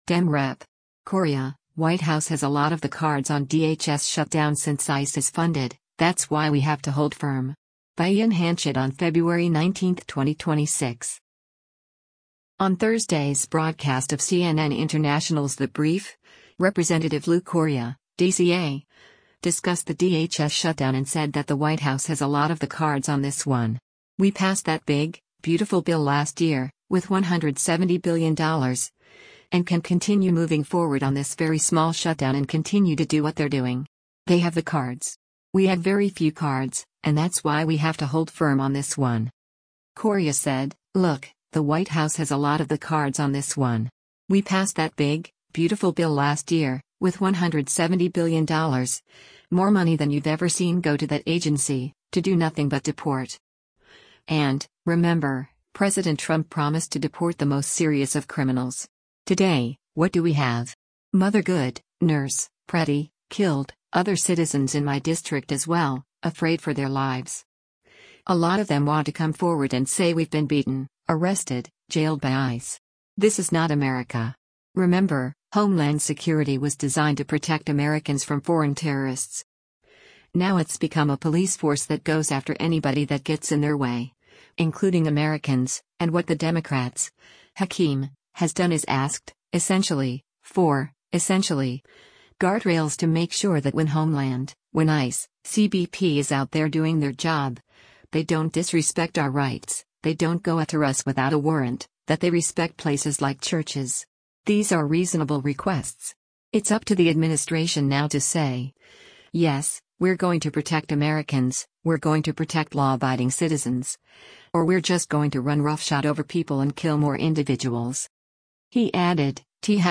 On Thursday’s broadcast of CNN International’s “The Brief,” Rep. Lou Correa (D-CA) discussed the DHS shutdown and said that “the White House has a lot of the cards on this one. We passed that big, beautiful bill last year, with $170 billion,” and “can continue moving forward on this very small shutdown and continue to do what they’re doing. They have the cards. We have very few cards, and that’s why we have to hold firm on this one.”